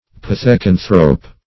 Pith`e*can"thrope, n. -- Pith`e*can"thro*poid, a.
pithecanthrope.mp3